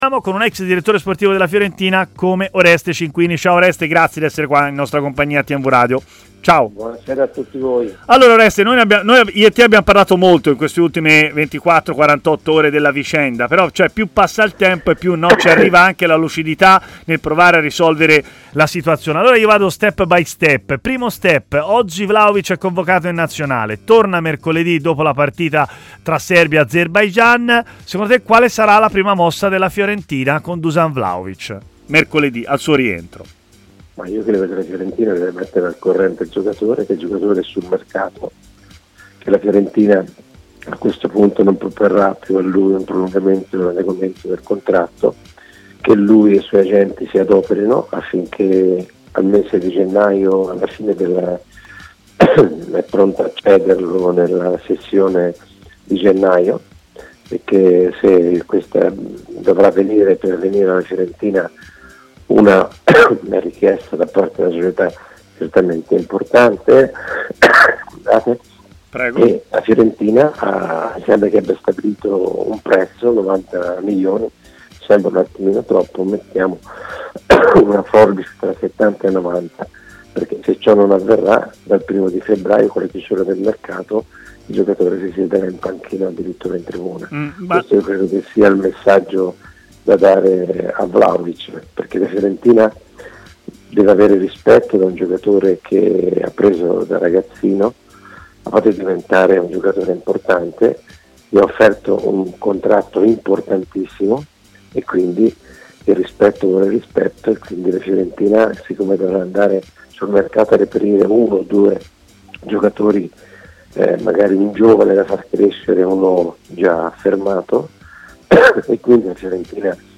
intervenuto a Stadio Aperto, trasmissione pomeridiana di TMW Radio